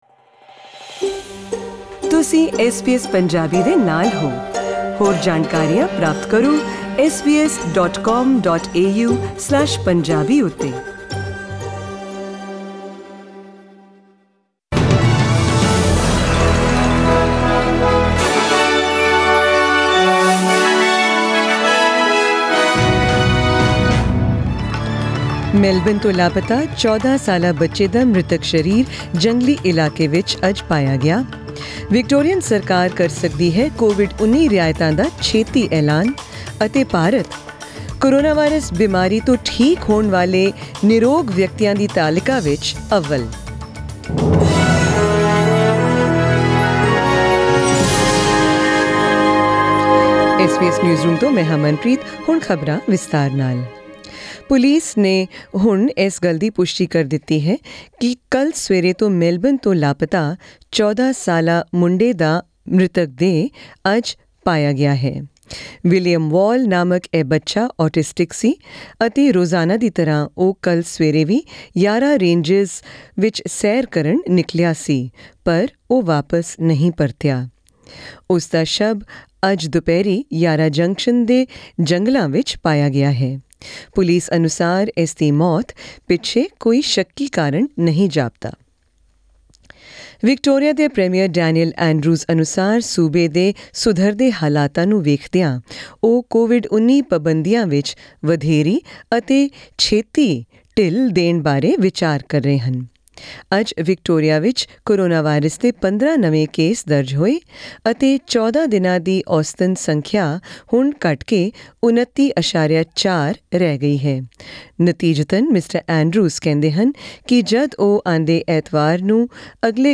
Headlines of tonight’s SBS Punjabi news bulletin: